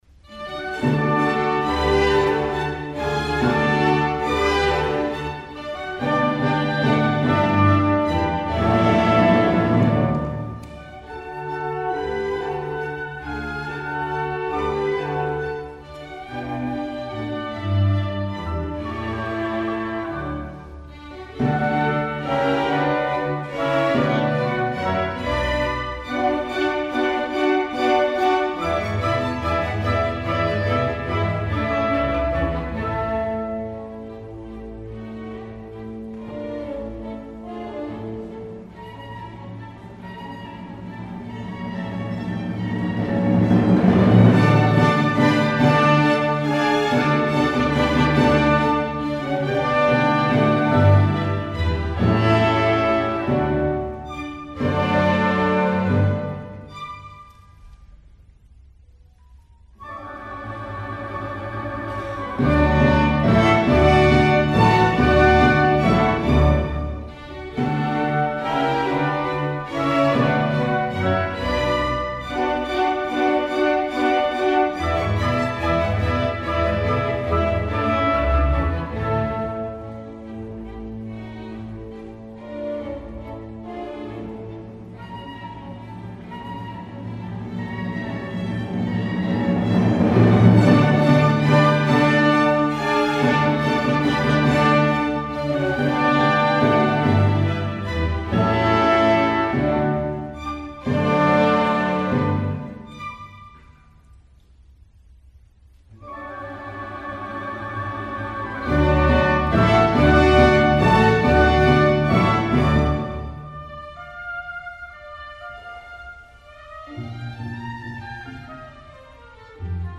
Grabación realizada por el equipo de exteriores de las Radios Públicas el 21 de marzo de 2026 en la Sala Eduardo Fabini del Auditorio Nacional Adela Reta.